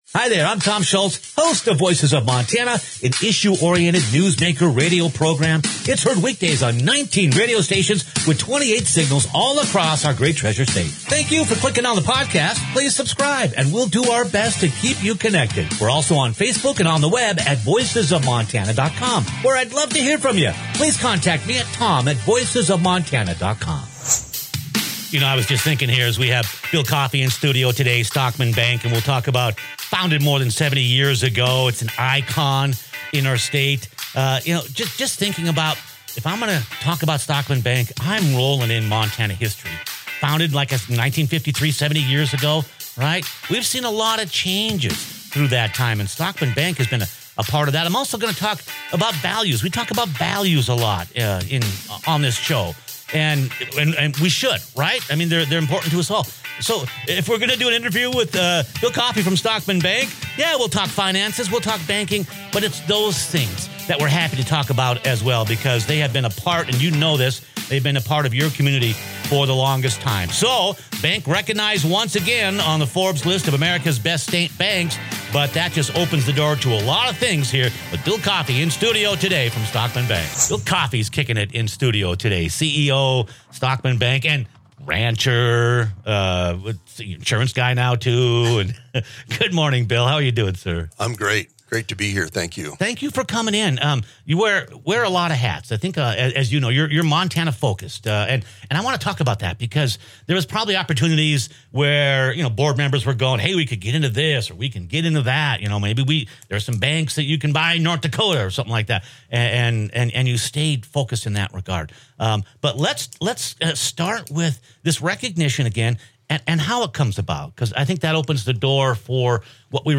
Click on the podcast for a chat about Montana history, values, ranching, business, and banking